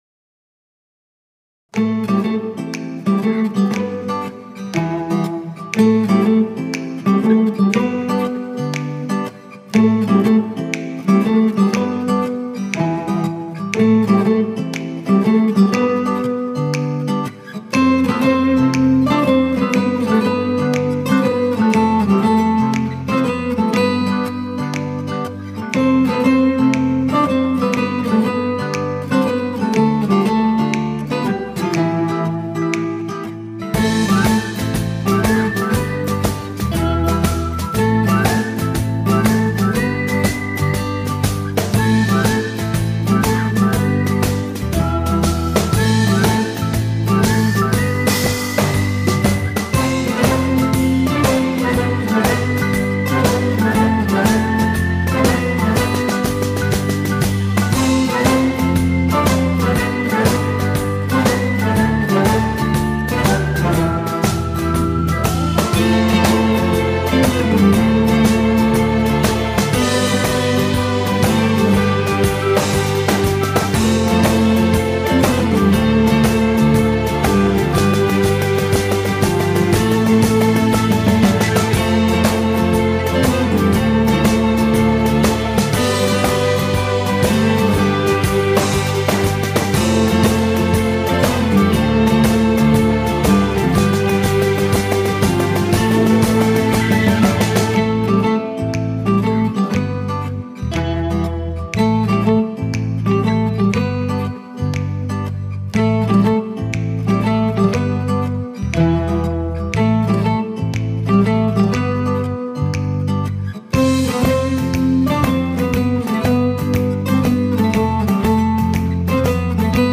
tema dizi müziği, mutlu huzurlu rahatlatıcı fon müziği.